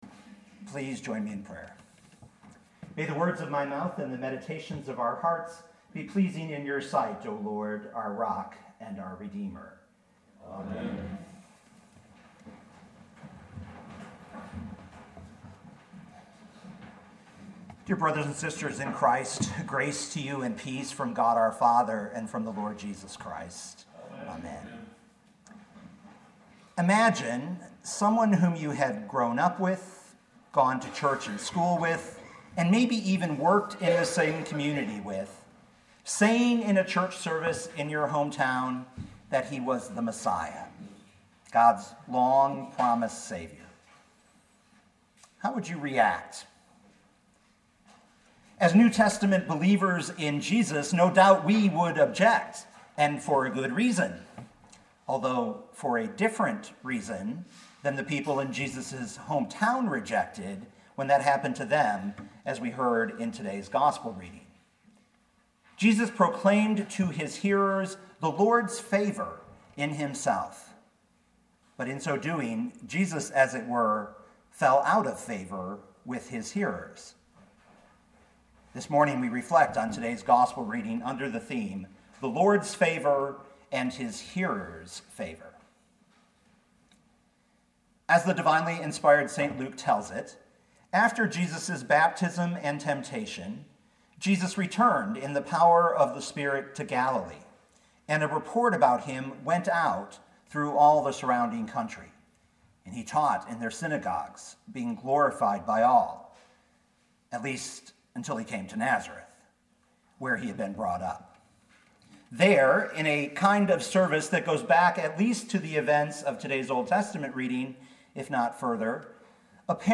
2019 Luke 4:16-30 Listen to the sermon with the player below, or, download the audio.